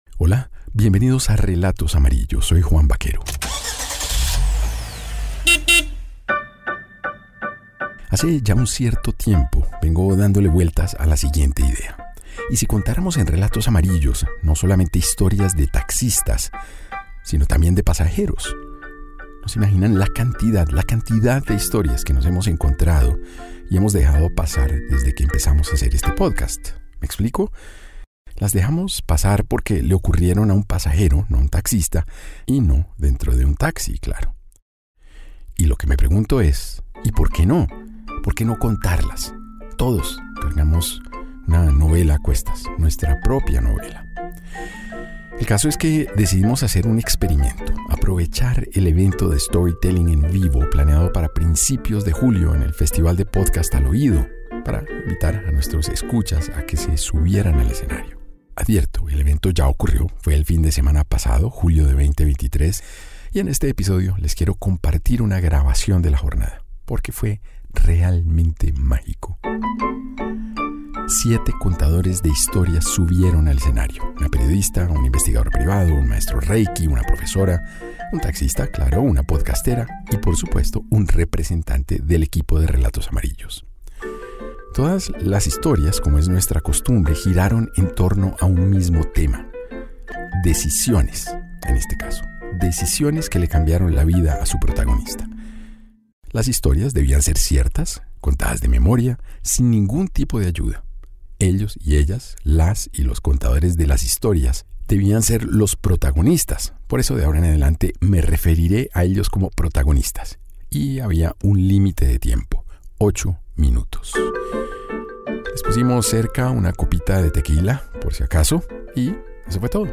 Revive Decisiones, el evento de storytelling de Relatos Amarillos en el Festival de Podcast Al Oído en la plataforma de streaming RTVCPlay.
Verse en medio de una balacera, entrar al quirófano, ser mamá, pedir ayuda y elegir vivir cada día no son decisiones sencillas. Estas son las historias que hicieron parte de nuestro evento de storytelling en el Festival de Podcast Al Oído.